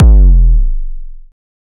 EDM Kick 20.wav